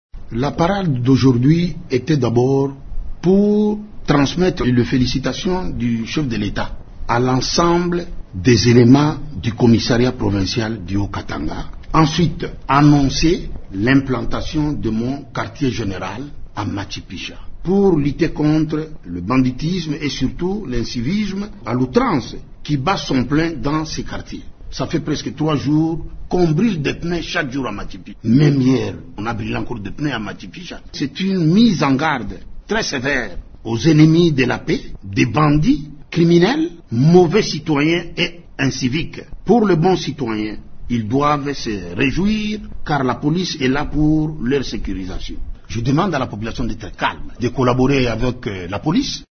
L’objectif de cette mesure: combattre l’insécurité qui prévaut depuis quelque temps dans ce quartier, l’une des plus populaires de Lubumbashi, a expliqué aux journalistes le général Banza lors d’une parade organisée samedi.